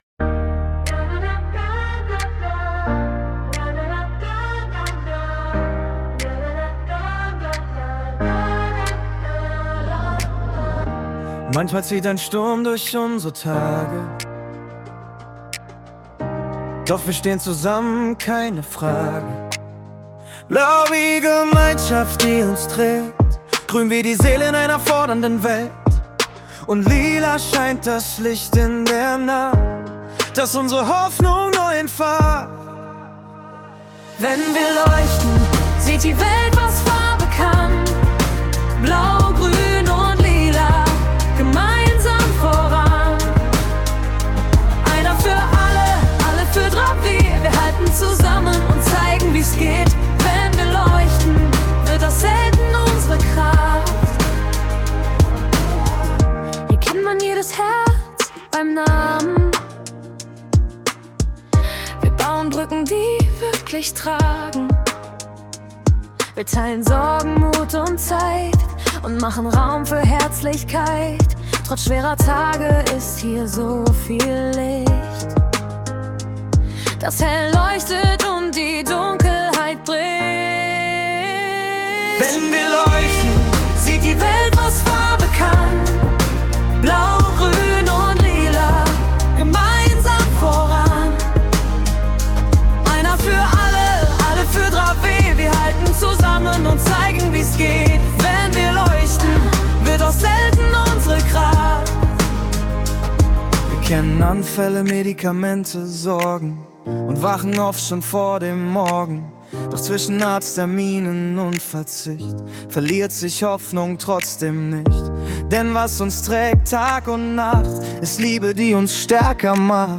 Wenn-wir-leuchten-Pop-Hymne_Ballade.mp3